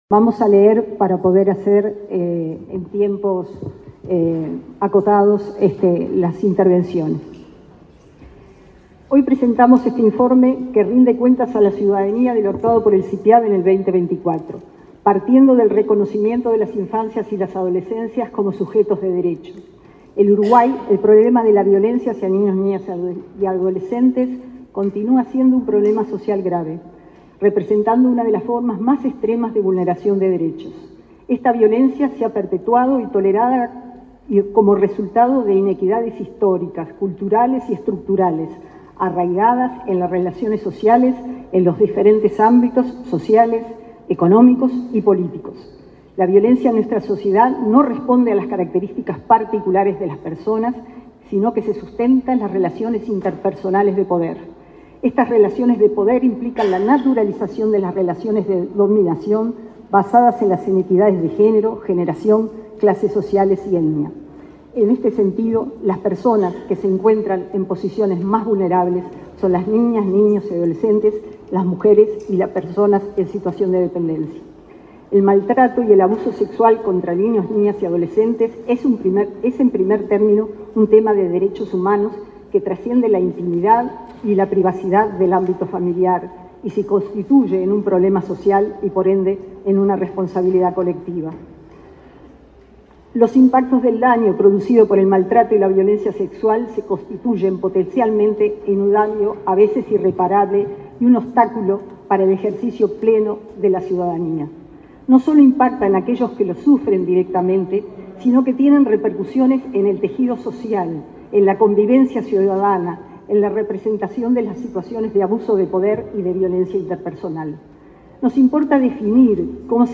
Palabras de autoridades en acto del Sipiav
Palabras de autoridades en acto del Sipiav 25/04/2025 Compartir Facebook X Copiar enlace WhatsApp LinkedIn El Sistema Integrado de Protección a la Infancia y a la Adolescencia contra la Violencia (Sipiav), presentó, este viernes 25 en el Palacio Legislativo, el informe de gestión 2024. En la oportunidad, se expresaron la directora de esa repartición, María Elena Mizrahi; la ministra de Salud Pública, Cristina Lustemberg; la presidenta del Inau, Claudia Romero, y la vicepresidenta de la República, Carolina Cosse.